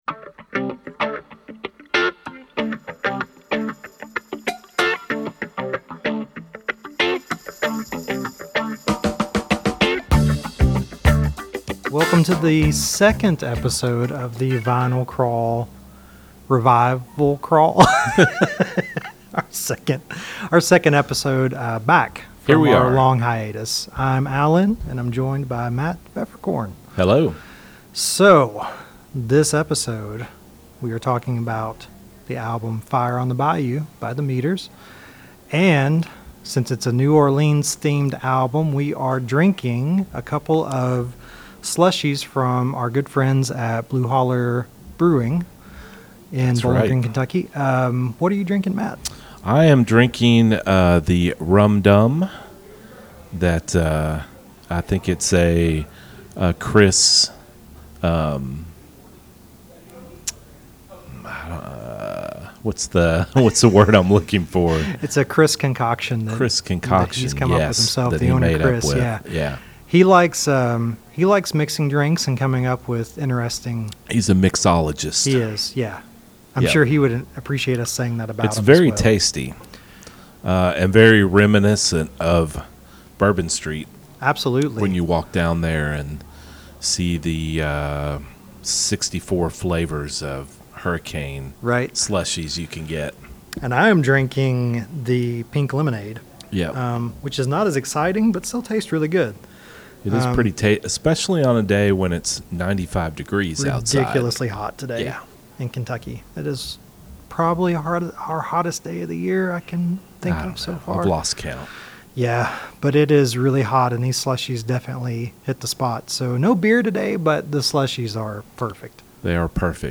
on location at Blue Holler Brewing in Bowling Green, Kentucky